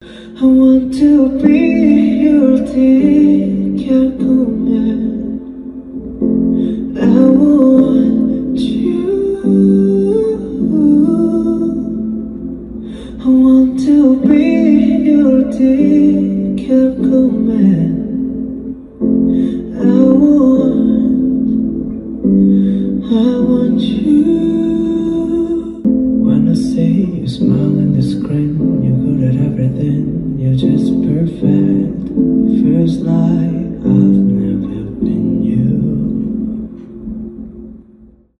медленные , романтические , поп , баллады